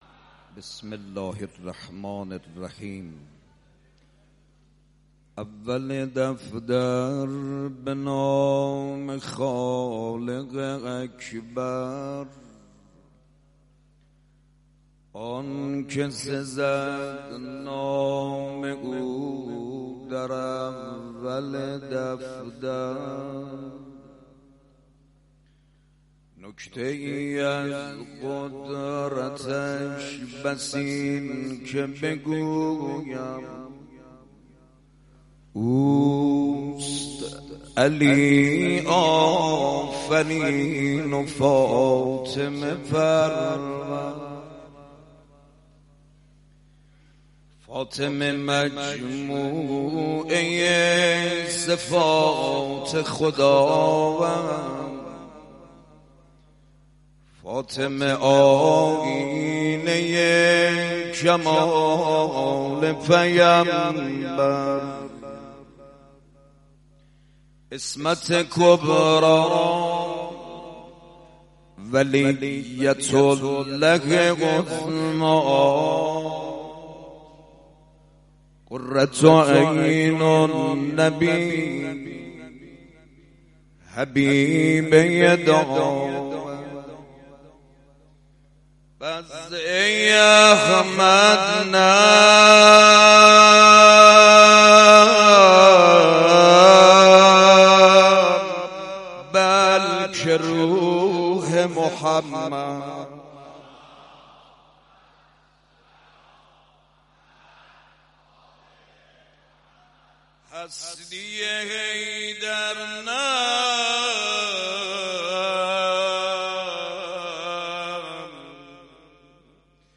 مداحی مداحان اهل بیت(ع) در دیداربا رهبر معظم انقلاب